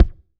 B.B KICK10.wav